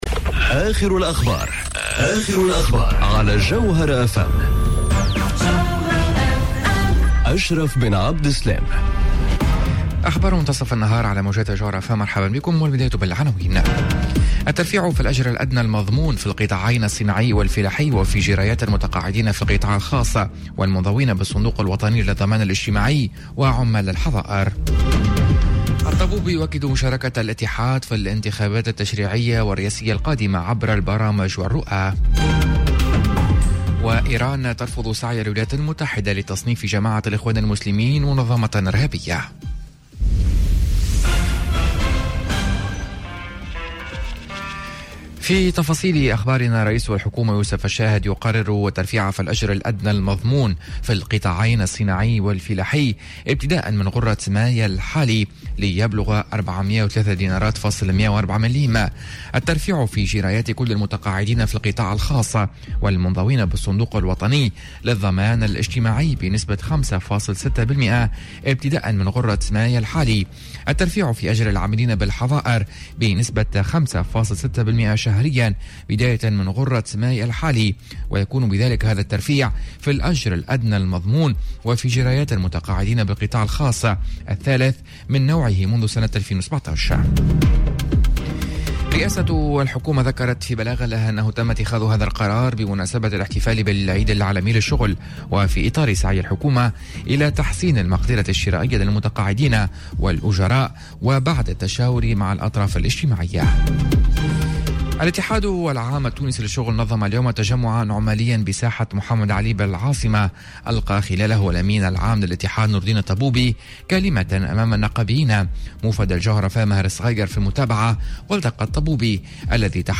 Journal Info 12h00 du mercredi 01 mai 2019